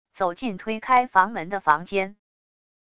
走进推开房门的房间wav音频